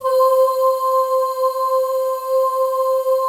C4 FEM OOS.wav